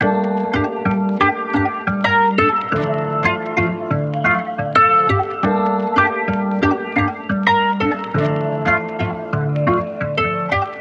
MELODY LOOPS
Beacon (177 BPM – Gm)
UNISON_MELODYLOOP_Beacon-177-BPM-Gm.mp3